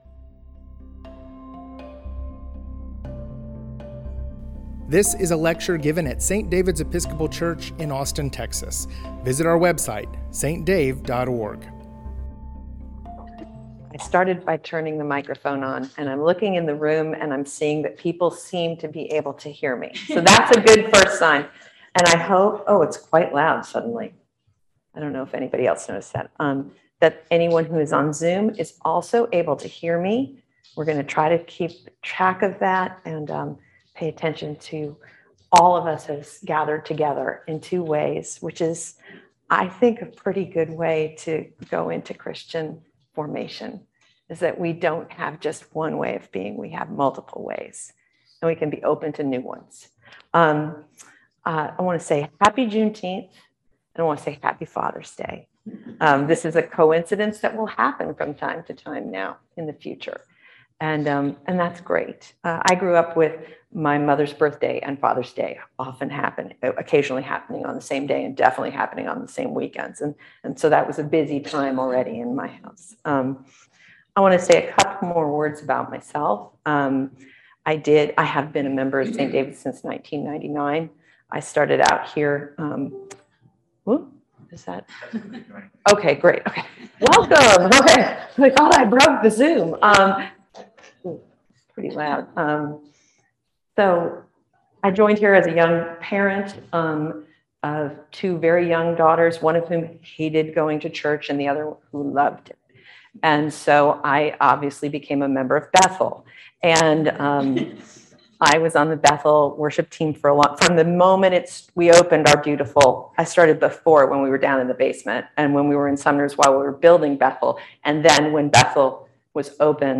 Lecture: On Juneteenth